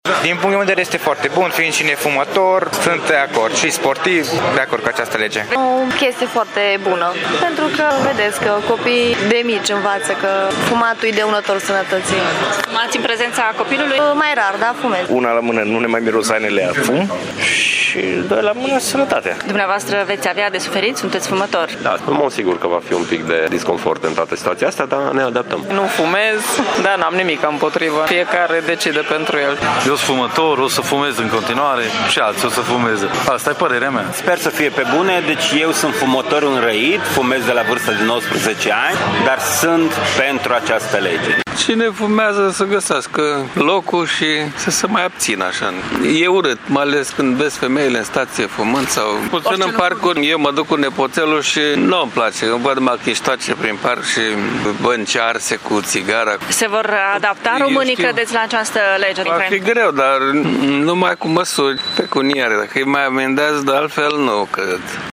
Târgumureșenii întâlniți azi de reporterul RTM salută această lege, deși unii dintre ei nu cred că se va schimba ceva, iar in baruri se va fuma în continuare: